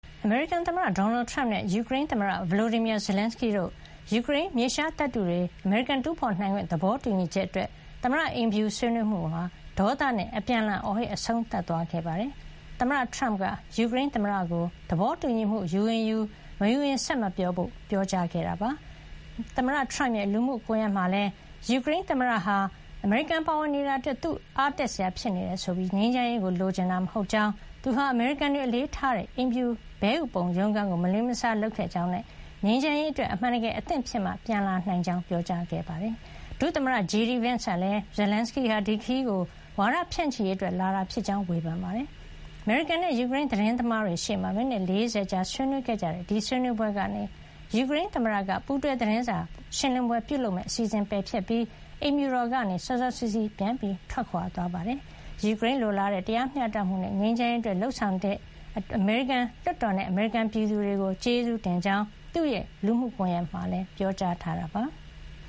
Trump - Zerlenshyy ဆွေးနွေးပွဲ ပြန်လှန်အော်ဟစ် ရပ်ဆိုင်း